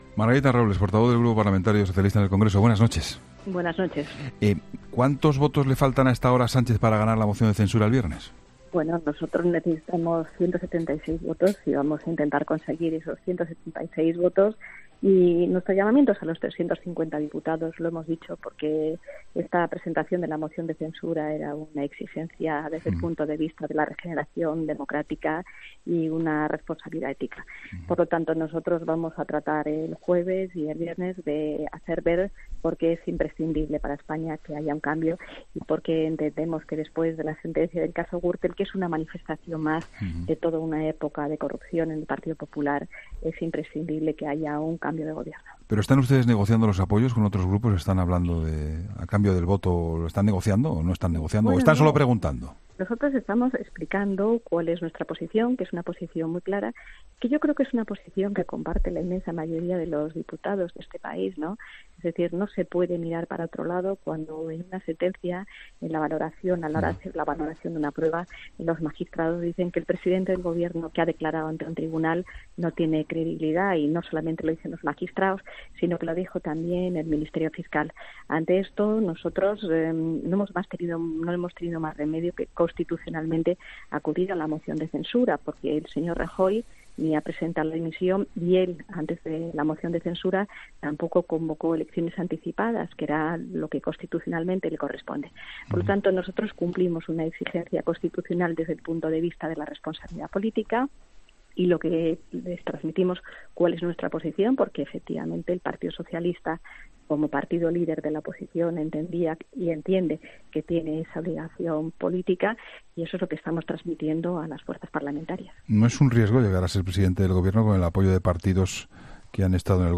La portavoz socialista en el Congreso, Margarita Robles, en 'La Linterna' con Juan Pablo Colmenarejo ha desvinculado la moción de censura del PSOE...